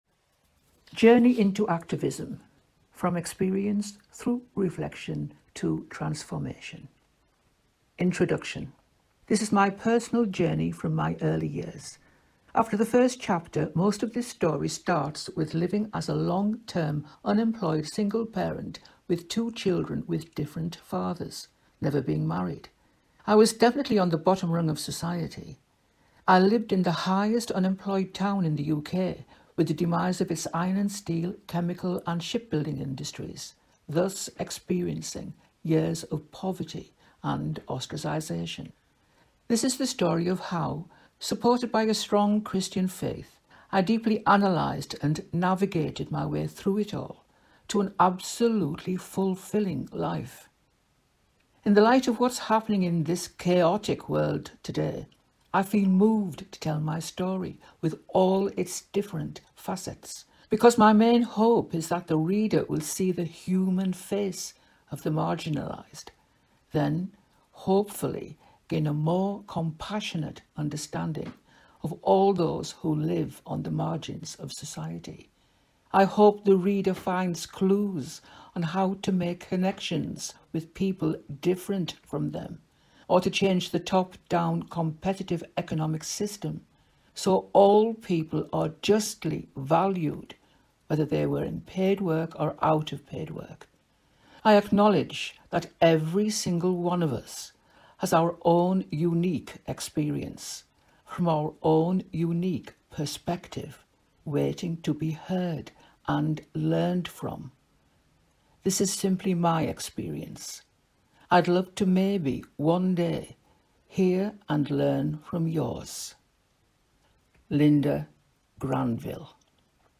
Dark Holy Ground: Audible Edition